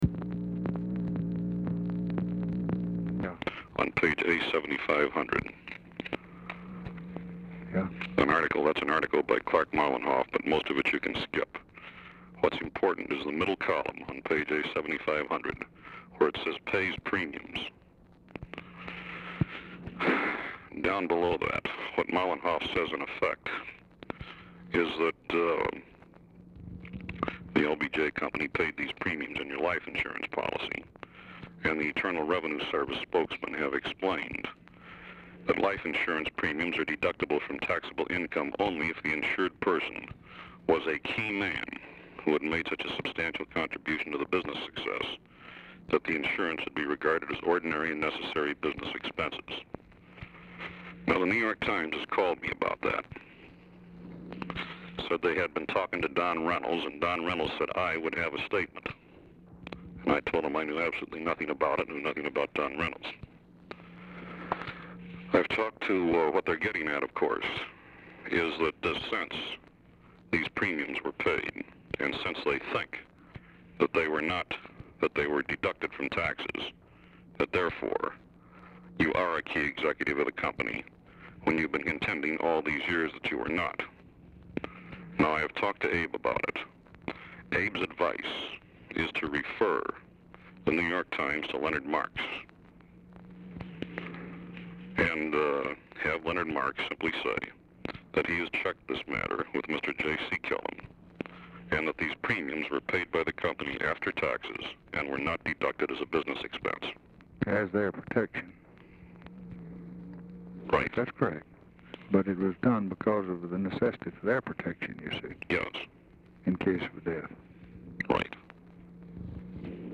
Telephone conversation # 396, sound recording, LBJ and GEORGE REEDY, 12/10/1963, 4:58PM | Discover LBJ
RECORDING STARTS AFTER CONVERSATION HAS BEGUN
Format Dictation belt
Location Of Speaker 1 Oval Office or unknown location